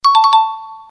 Fx Correcto Sound Effect Download: Instant Soundboard Button